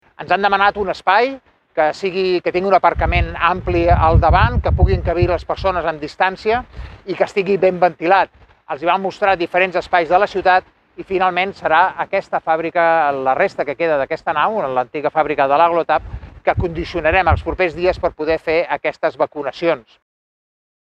L’alcalde del municipi, Carles Motas, explica en un vídeo publicat per l’Ajuntament que, amb aquestes vacunacions massives, es podrien administrar més de 1.000 dosis a la setmana.